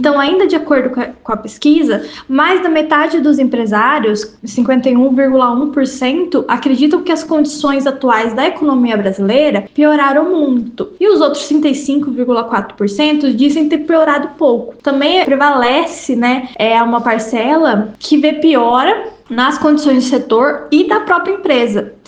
Em entrevista ao programa da FM Educativa MS 104.7 “Agora 104”